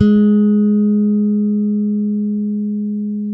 -MM JAZZ G#4.wav